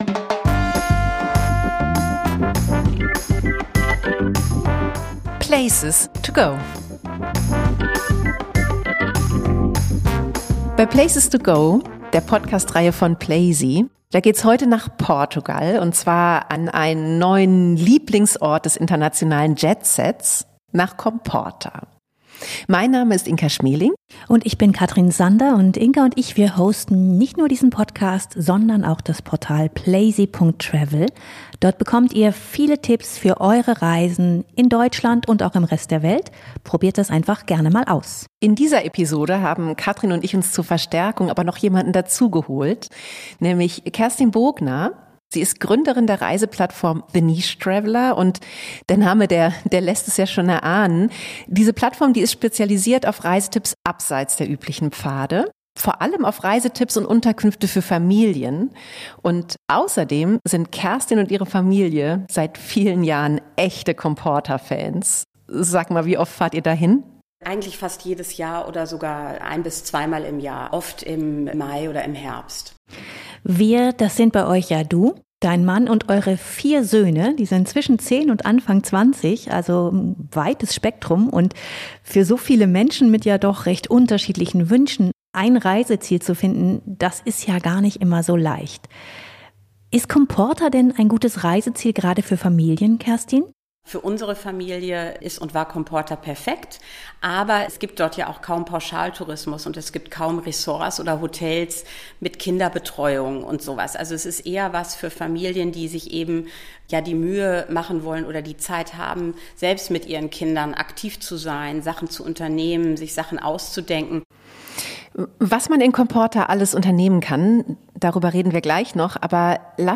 Interviewgast